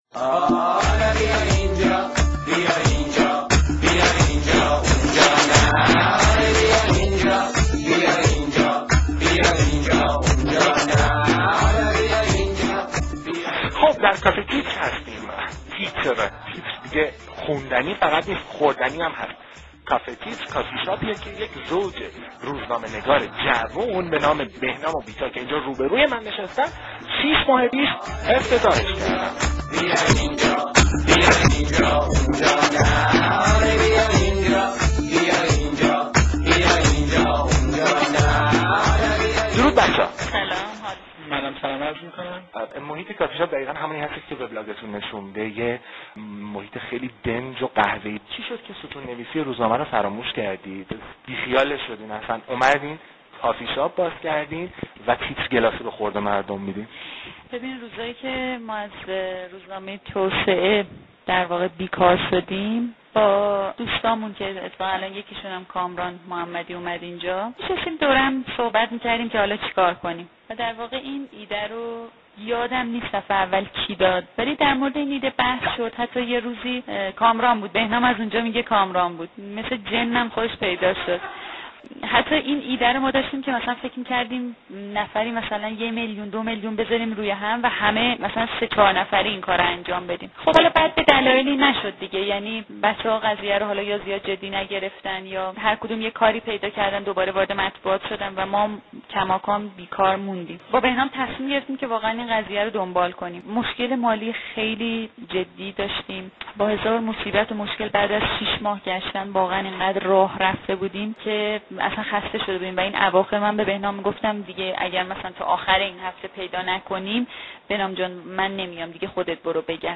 گوش کنین محیطش چطوریه ، ماهم همینو می خوایم ولی یکخورده ...؟ تر.